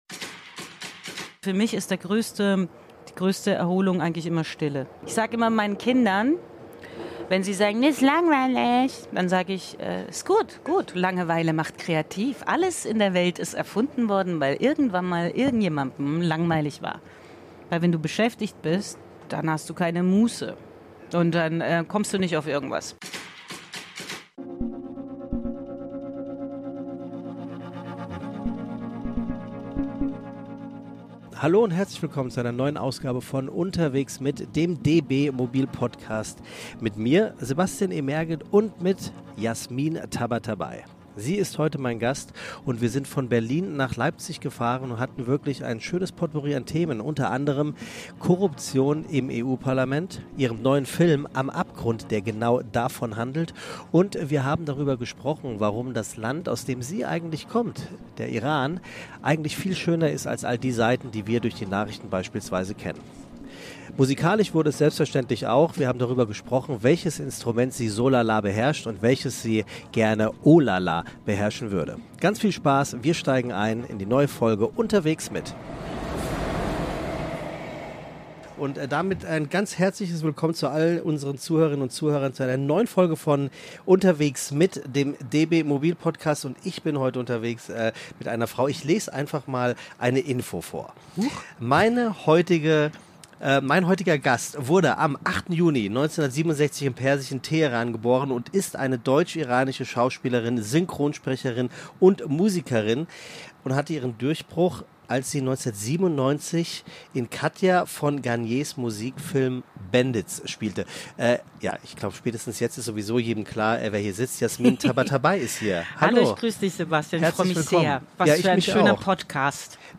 Ein spannendes, lebendiges Gespräch über Jasmins Liebe zum Musikmachen, die strenge Diät ihrer Katze, ihren neuen ARD-Thriller „Am Abgrund“ und darüber, was sie mit Queen Latifah gemein hat.